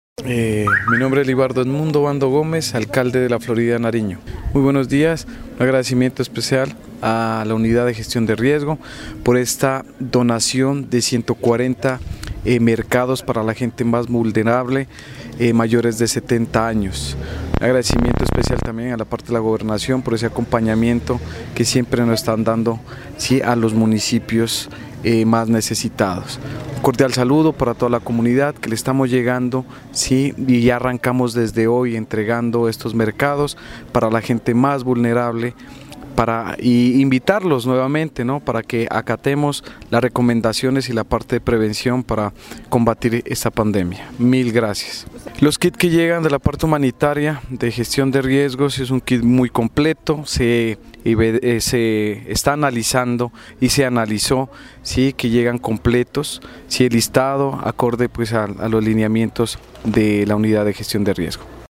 Testimonios:
Libardo-Gómez-Alcalde-La-Florida.mp3